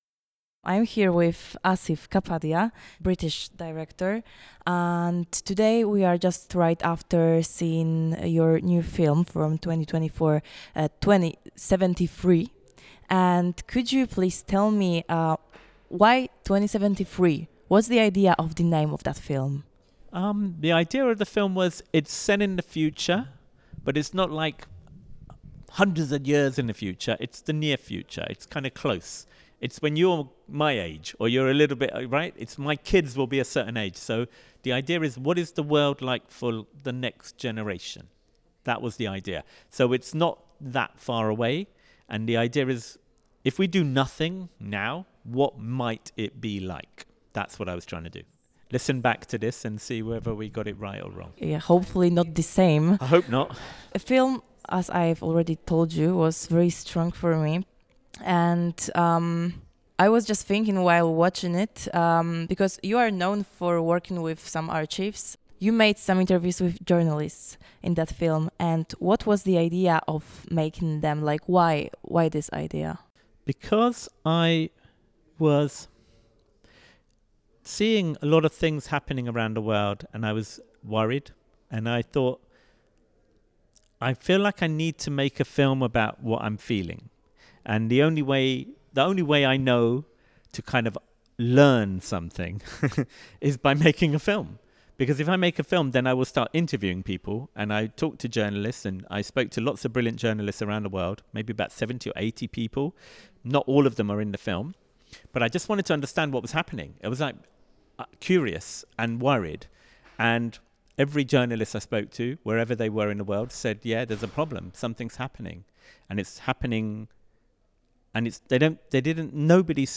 2073-wywiad-Asif-Kapadia.mp3